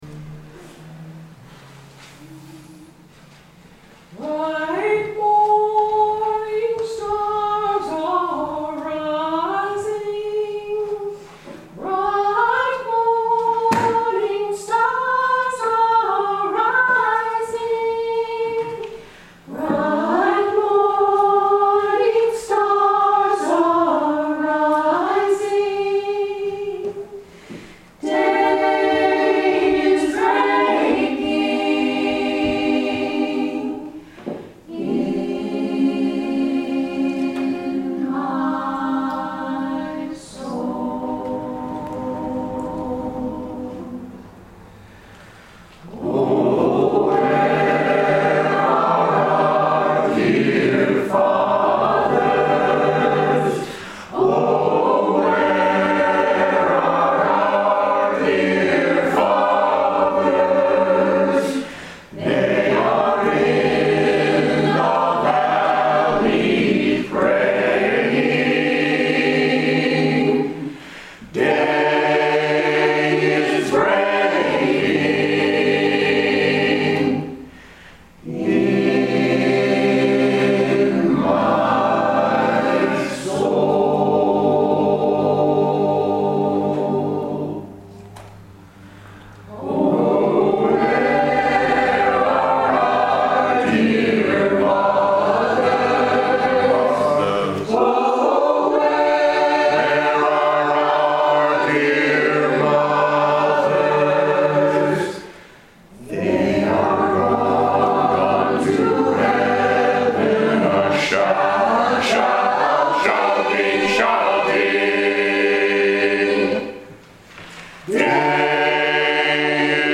And you NEED to hear how beautiful our choir sounds on a Sunday morning!